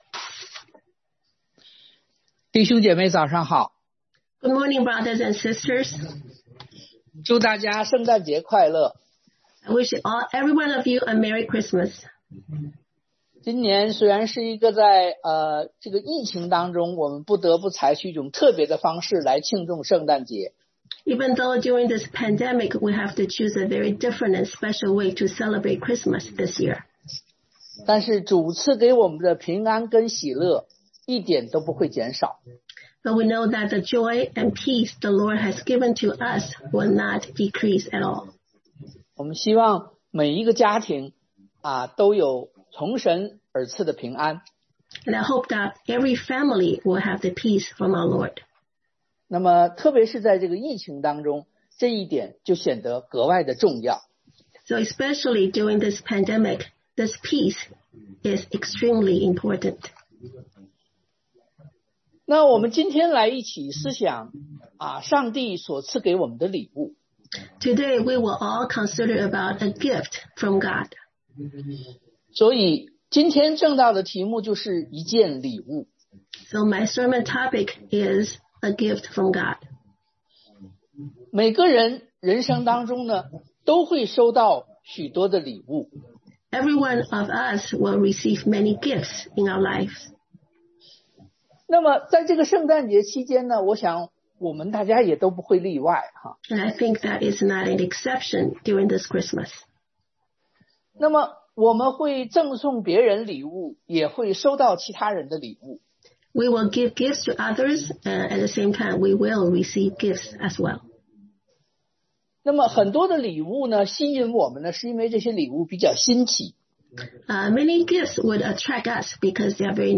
Christmas Special Worship 聖誕節特別崇拜一件禮物 A Gift from God
Service Type: Sunday AM Christmas Special Worship